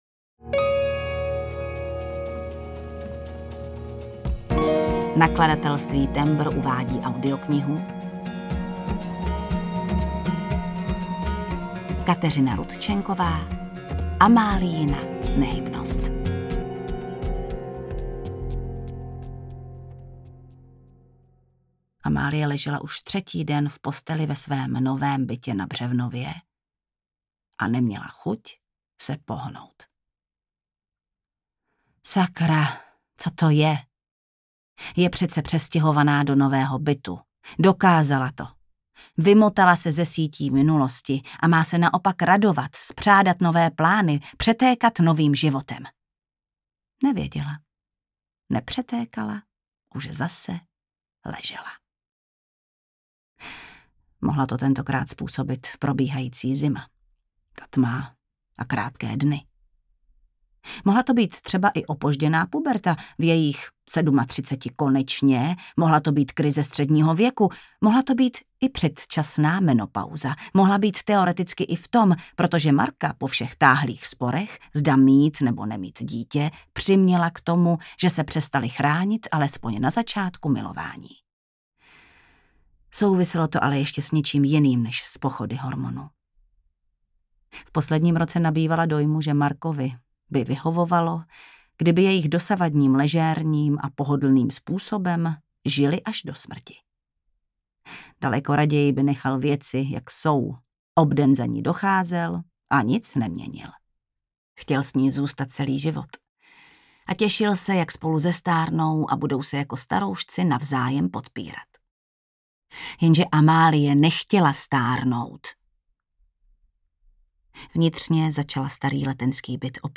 Audiokniha
Čte: Jana Stryková